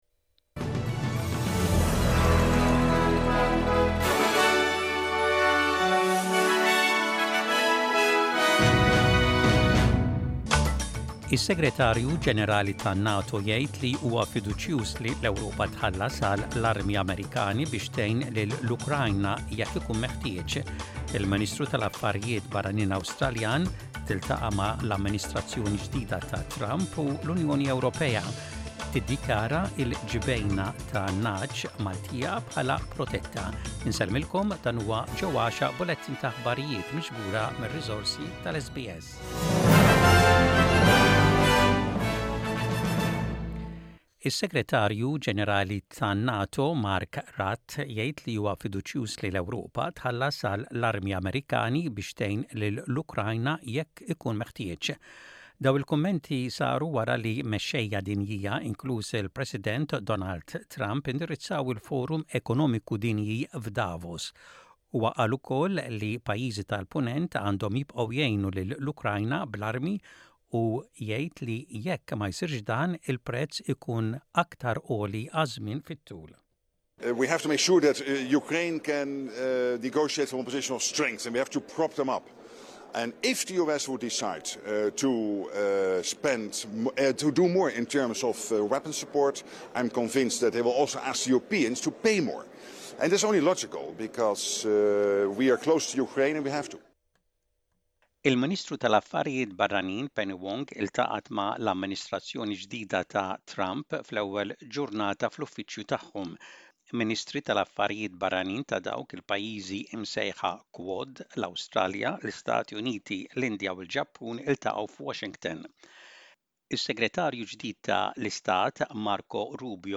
Aħbarijiet bil-Malti: 24.01.25